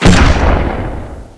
assets/common/sounds/weapons/panzer/shoot.wav at 16f6c8c14c9714af6e398f8d4aa3563ba2143ca3
shoot.wav